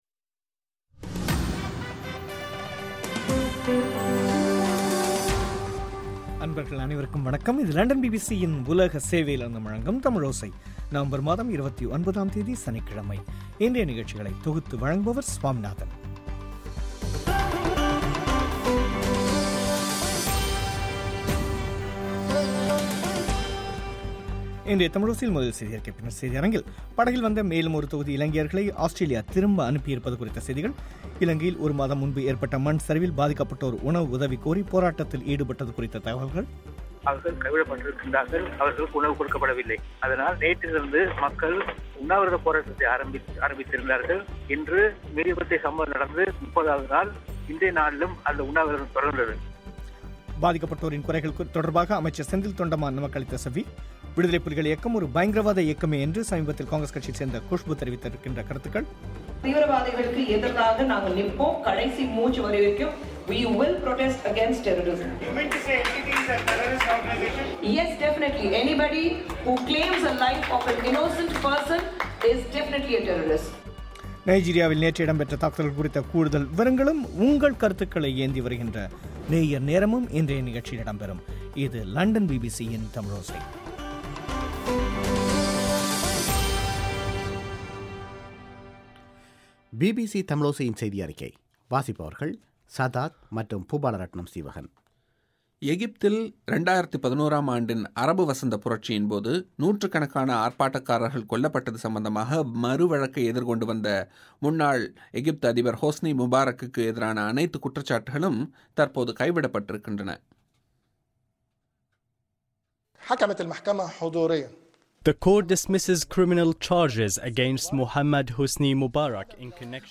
இலங்கையில் ஒரு மாதம் முன் ஏற்பட்ட மண் சரிவில் பாதிக்கப்பட்டோர் உணவு உதவி கோரி போராட்டத்தில் ஈடுபட்டது குறித்த தகவல்கள். பாதிக்கப்பட்டோரின் குறைகள் தொடர்பாக அமைச்சர் செந்தில் தொண்டமான் நமக்களித்த செவ்வி
படகில் வந்த மேலும் ஒரு தொகுதி இலங்கையர்களை ஆஸ்திரேலியா திரும்ப அனுப்பியுள்ளது குறித்த செவ்வி